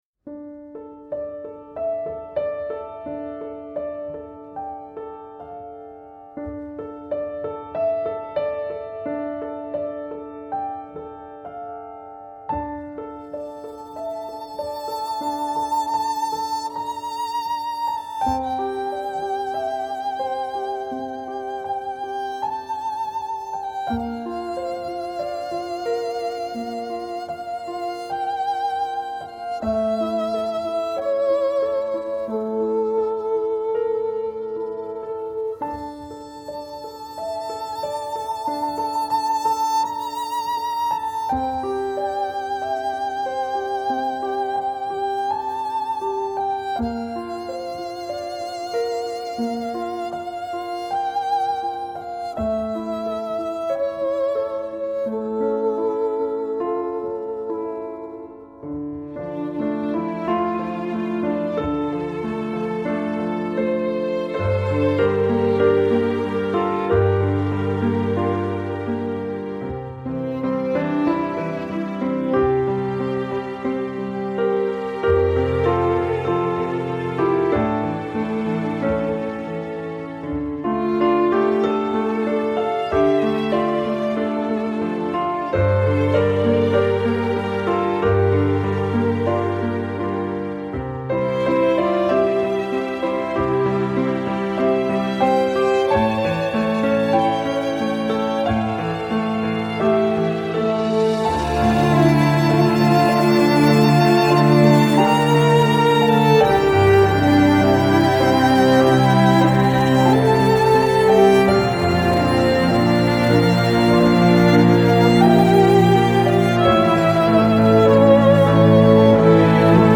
hauntingly beautiful and dramatic setting